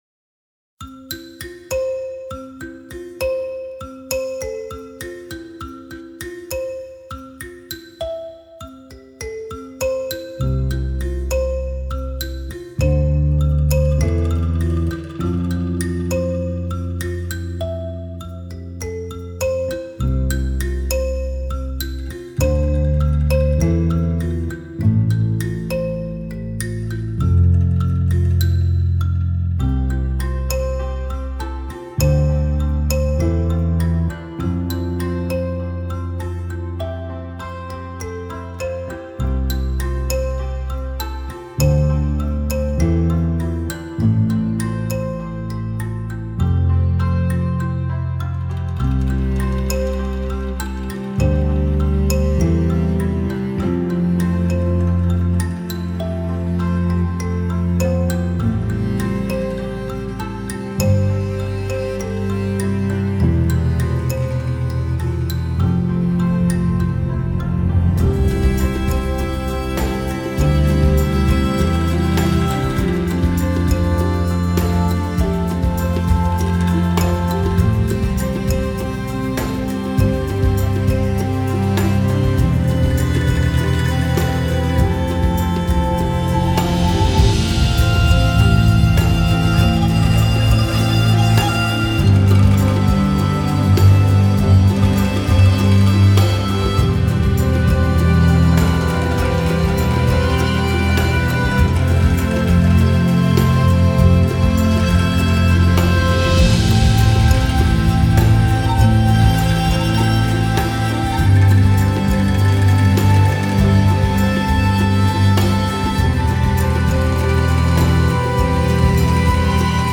Traditional tuned percussion
Plucked tones and beyond